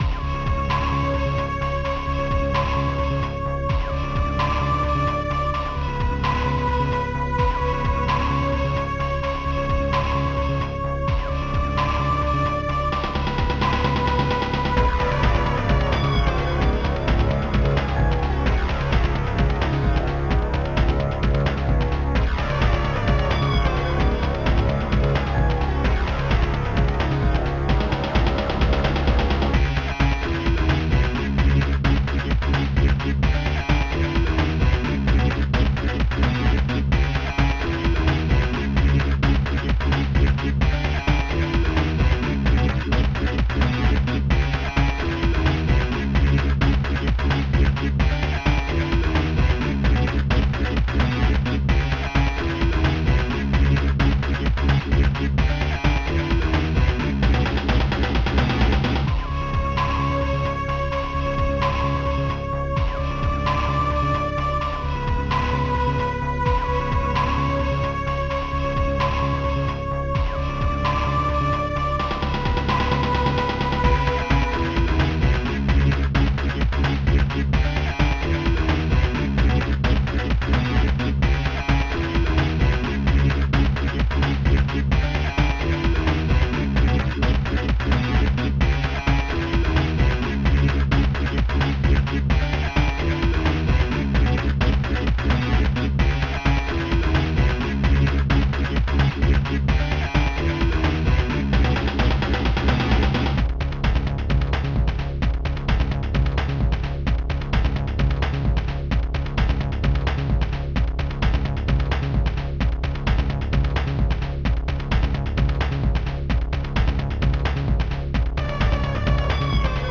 mod (ProTracker MOD (6CHN))
Fast Tracker 6CHN